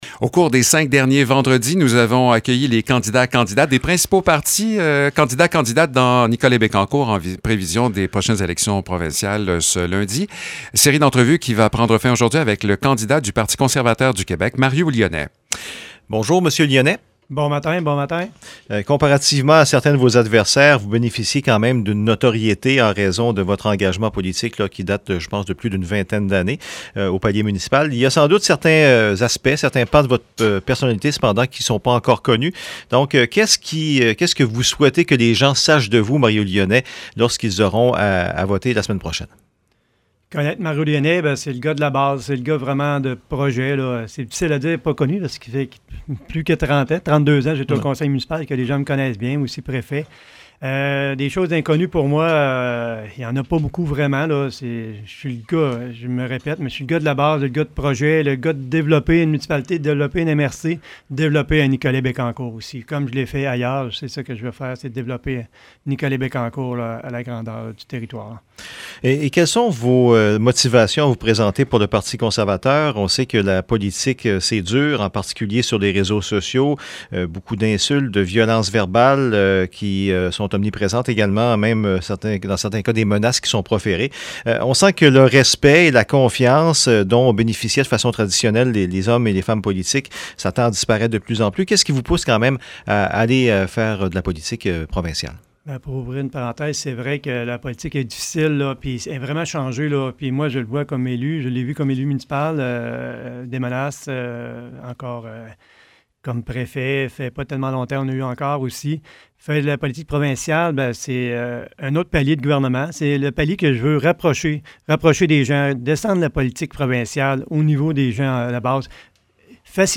Les cinq vendredis du mois de septembre, à 7h22, VIA 90.5 accueillait tour à tour les cinq candidats des principaux partis politiques aux élections du 3 octobre 2022 dans Nicolet-Bécancour.
Voici l’entrevue intégrale: https